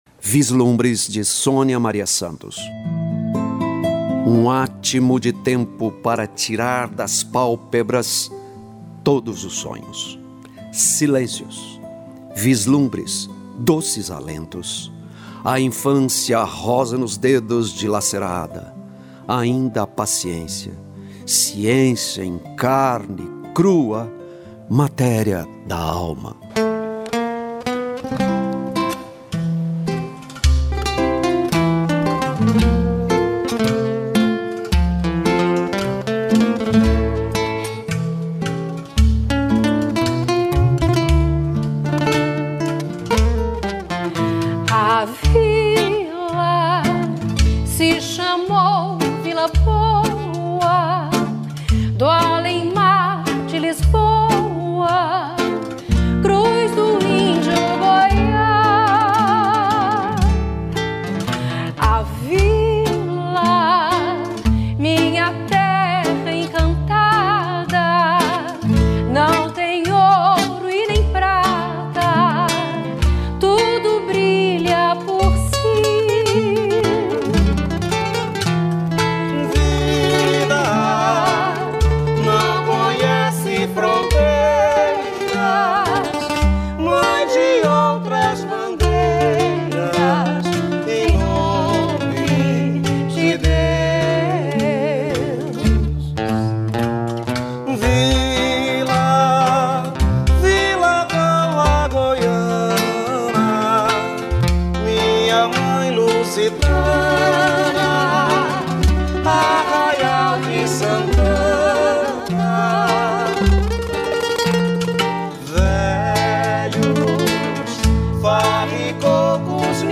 Poesia Falada…”Cem poemas essenciais”